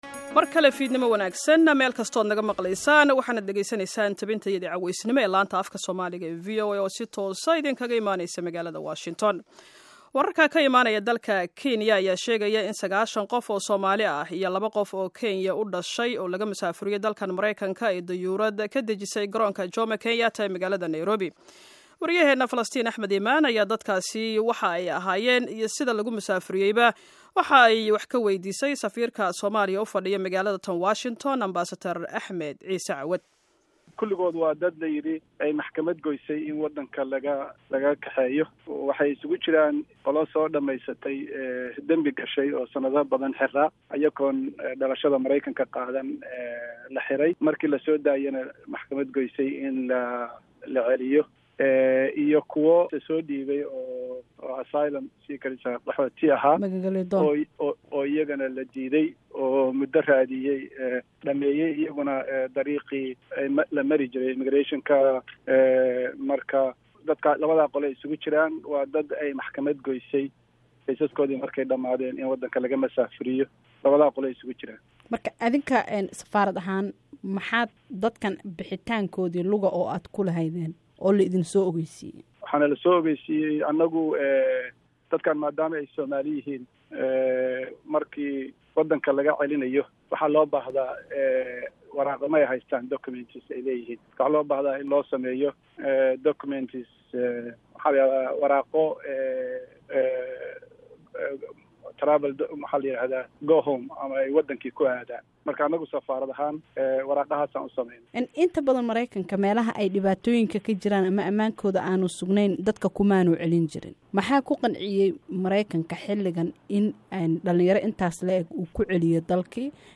Wareysi: Safiirka Soomaaliya ee USA